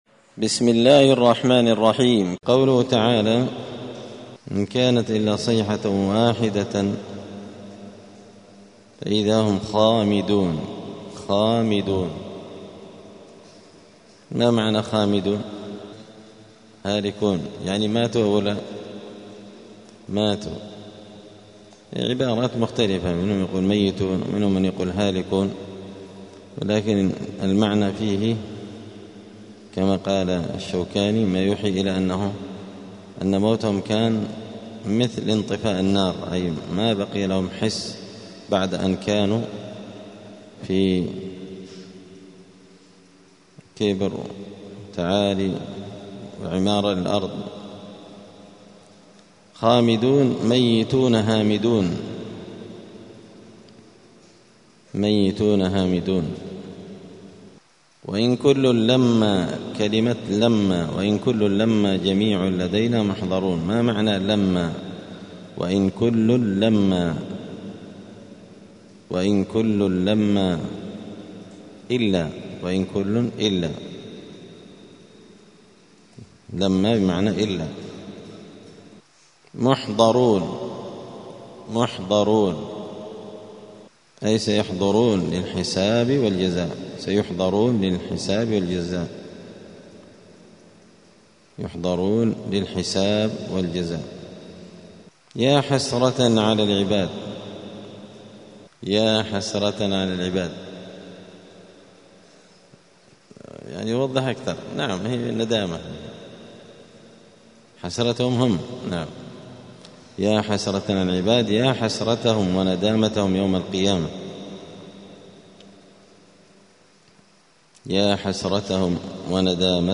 الجمعة 30 محرم 1447 هــــ | الدروس، دروس القران وعلومة، زبدة الأقوال في غريب كلام المتعال | شارك بتعليقك | 5 المشاهدات